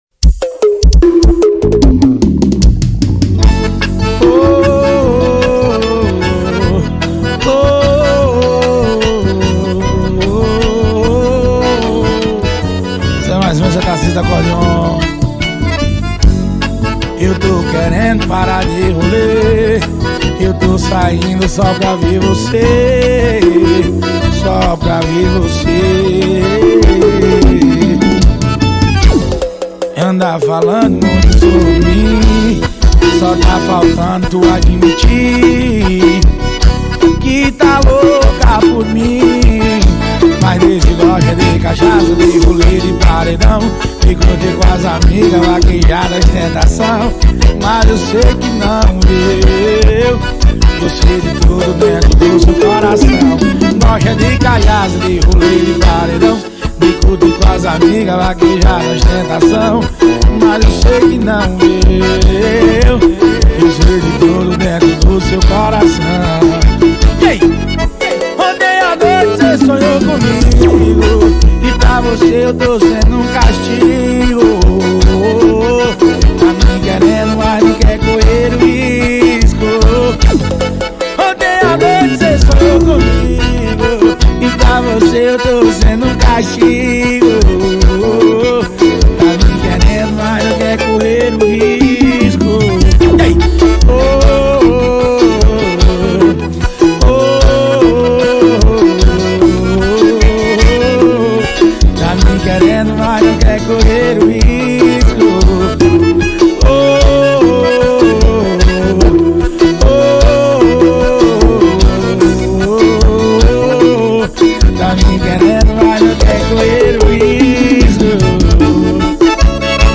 2024-11-07 11:01:24 Gênero: Forró Views